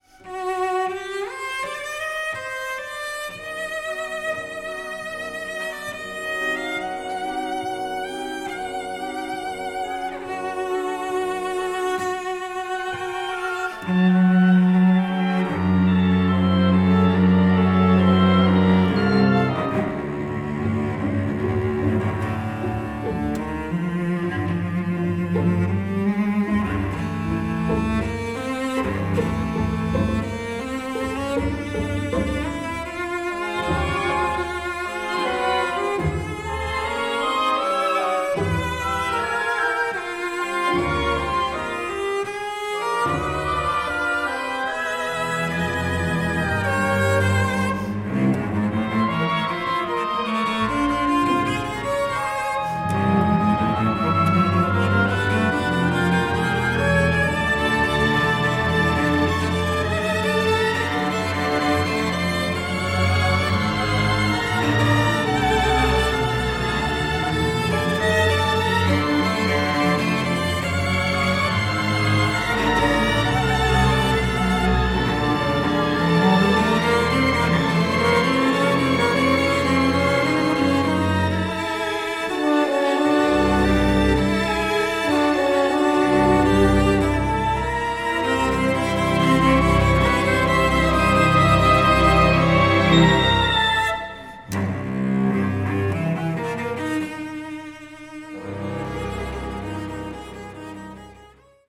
Neumann SM69 Schoeps MK Neumann M150
Orchestra
Millennia HV-3d & Avid 002
The Wharton Center, Great Hall .